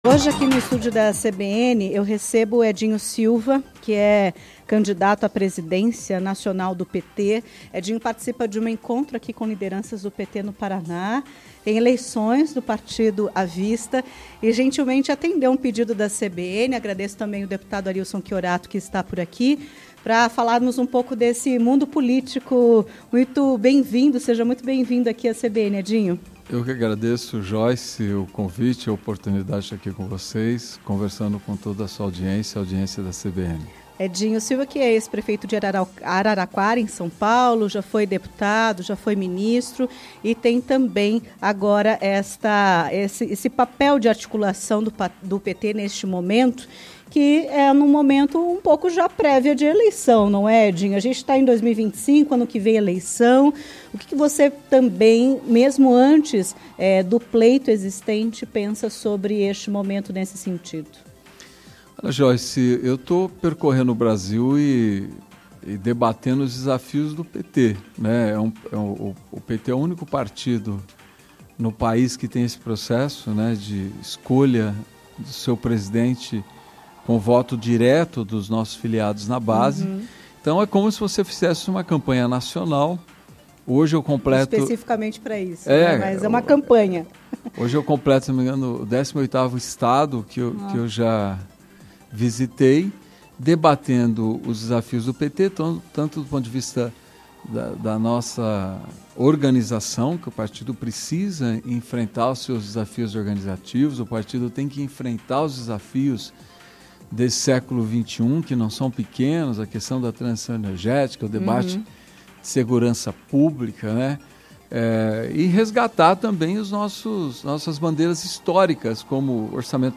Em entrevista à CBN Curitiba nesta quinta-feira (22), ele destacou o desafio de conversar com a nova classe trabalhadora.